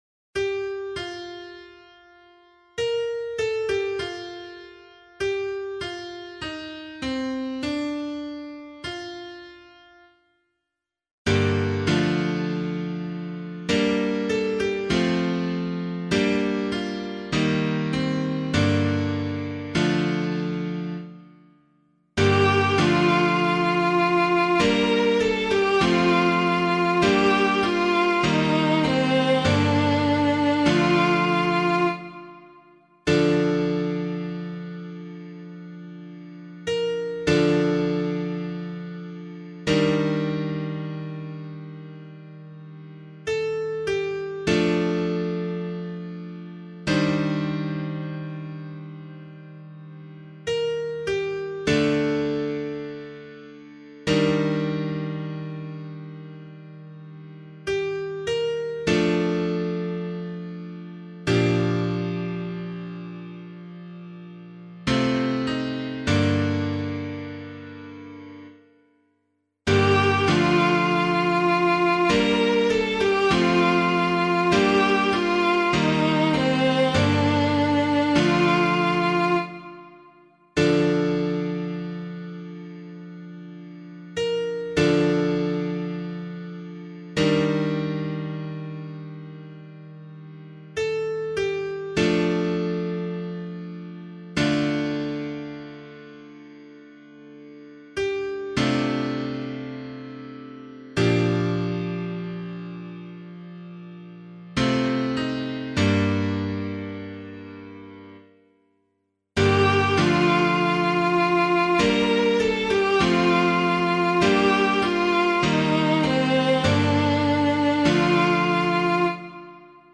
021 Good Friday Psalm [APC - LiturgyShare + Meinrad 3] - piano.mp3